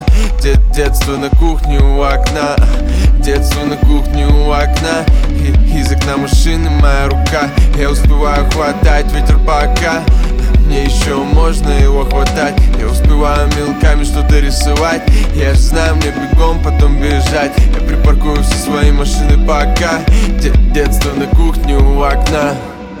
русский рэп
грустные